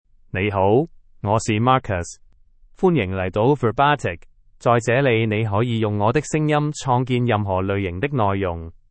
Marcus — Male Chinese (Cantonese, Hong Kong) AI Voice | TTS, Voice Cloning & Video | Verbatik AI
Marcus is a male AI voice for Chinese (Cantonese, Hong Kong).
Voice: MarcusGender: MaleLanguage: Chinese (Cantonese, Hong Kong)ID: marcus-yue-hk
Voice sample
Listen to Marcus's male Chinese voice.